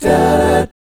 1-EMI7    -R.wav